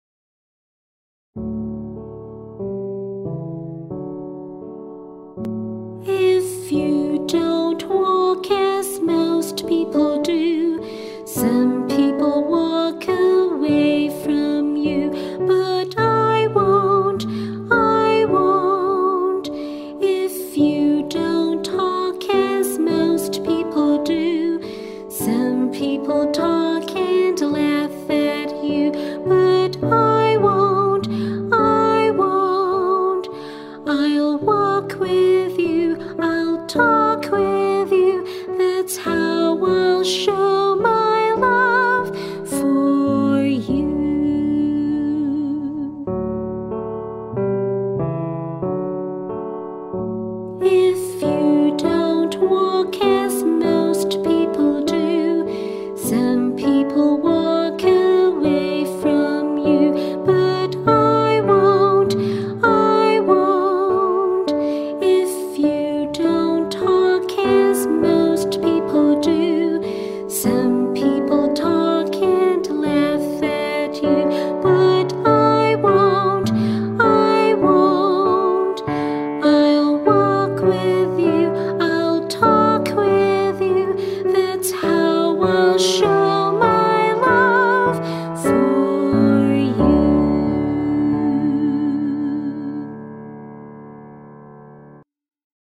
Music & Vocals Video Discrimination